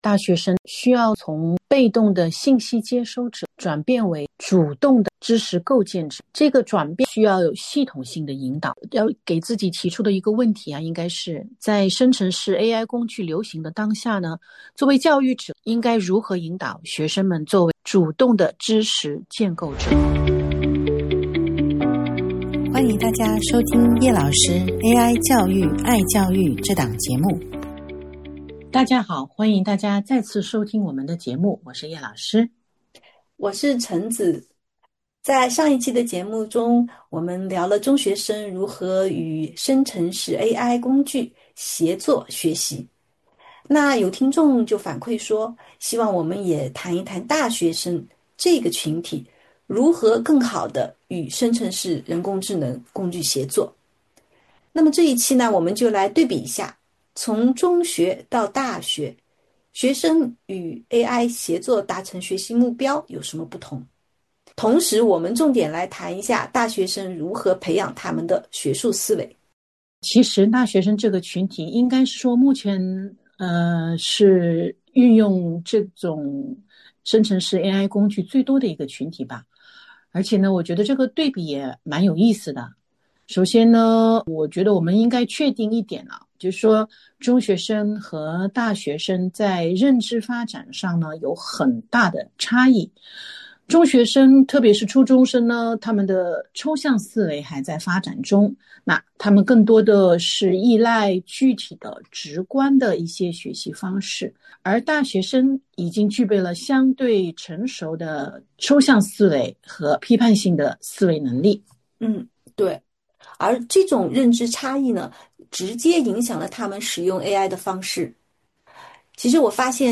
本期节目，两位主持人讨论的主题是如何引导大学生使用，与人工智能合作。